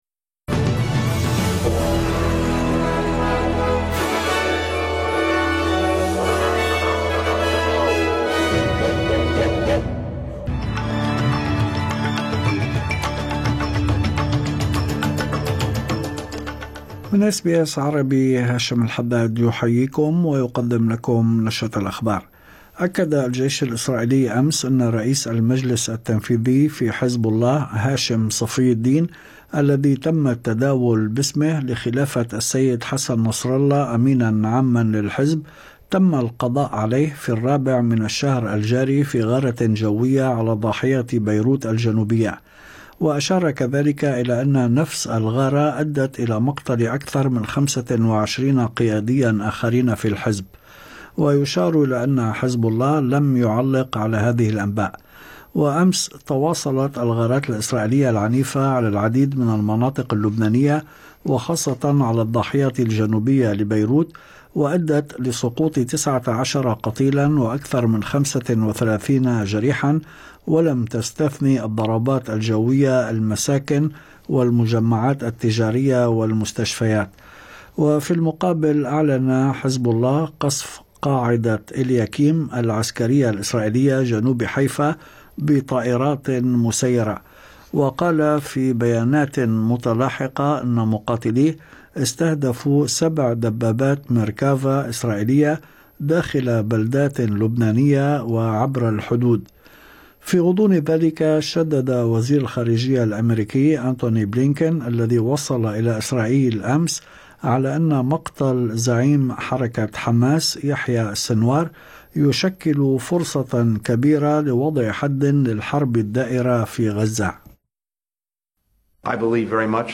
نشرة أخبار الظهيرة 23/10/2024